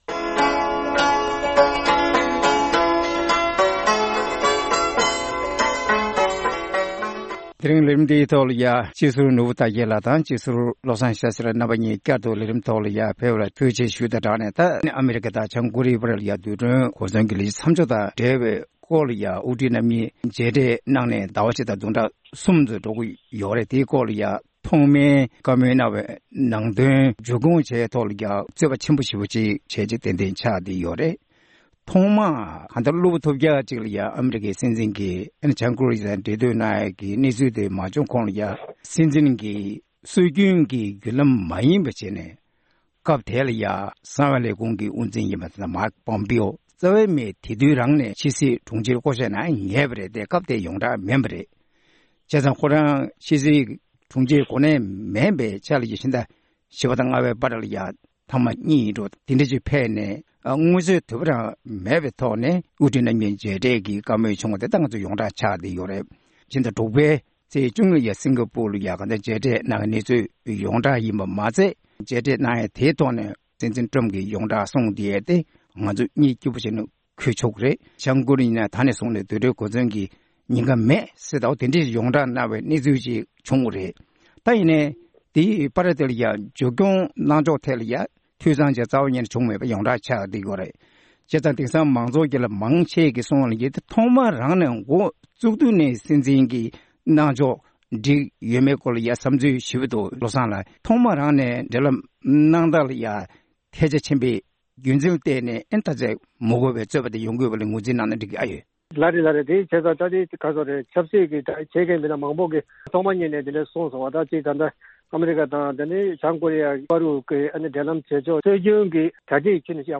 ཨ་རི་དང་བྱང་ཀོ་རི་ཡའི་དབུ་ཁྲིད་དབར་མཇལ་འཕྲད་ཐོག་ནས་ཀོ་རིའི་ཡའི་ཕྱེད་གླིང་ནང་རྡུལ་ཕྲན་གོ་མཚོན་མེད་པ་བཟོ་རྒྱུའི་གྲོས་མཐུན་མཇུག་སྐྱོང་ཐུབ་མིན་ཐད་གླེང་མོལ།